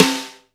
Brush Rimshot.wav